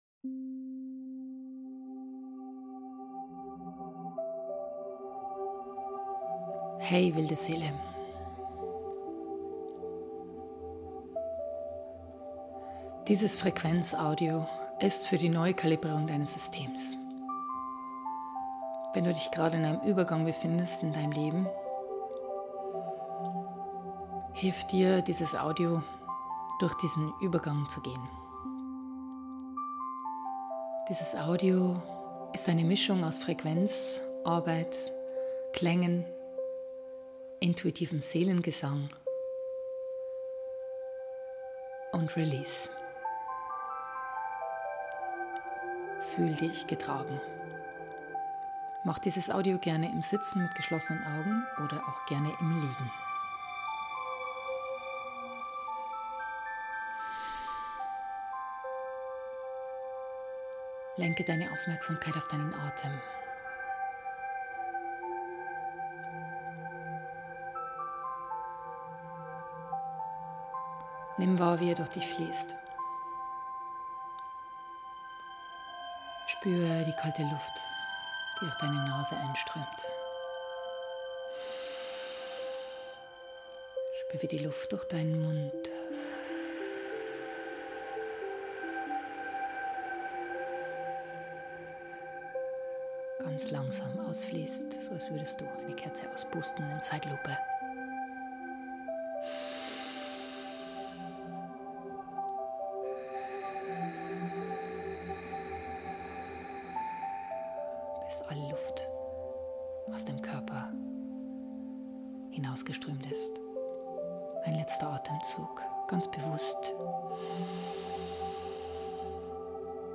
Dies Frequenzsession mit eigens komponierten Seelenklang und Seelengesang kann dich in Übergangsphasen unterstützen.